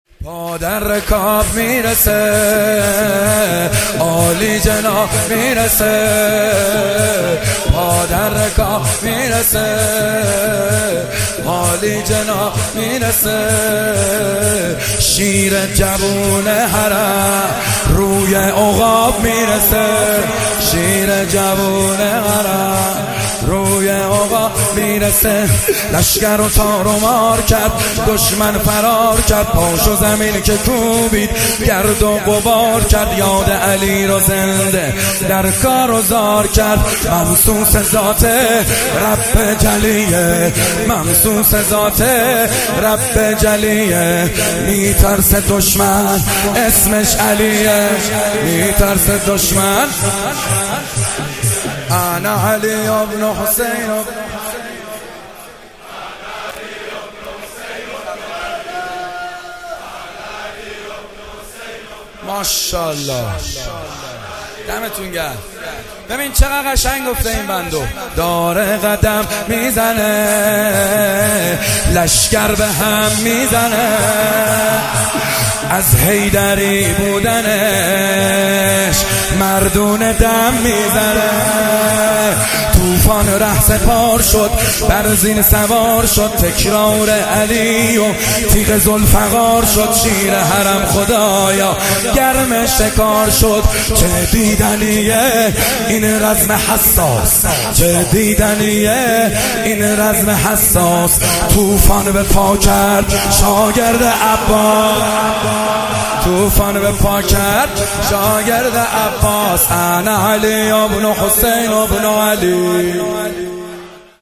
مداحی جدید کربلایی محمدحسین حدادیان شب ششم محرم 97 مهدیه امام حسین
شور